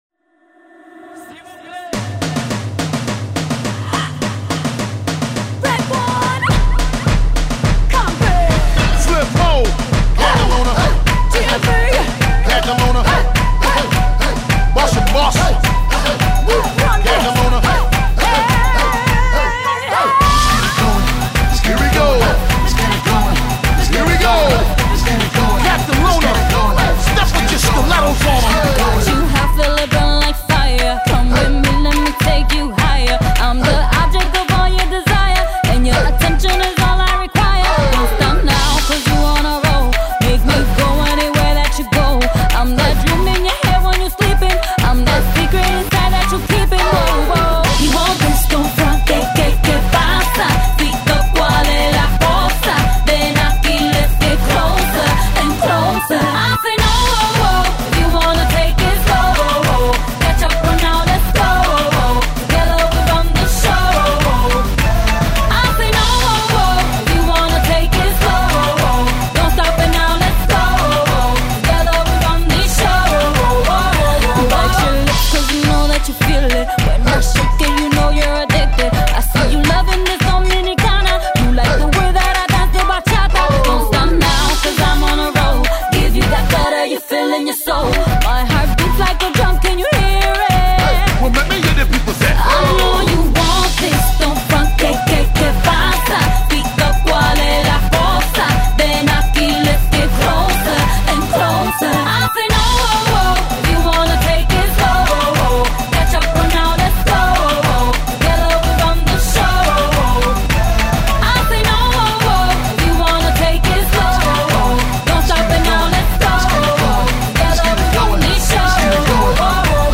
Зажигательная песня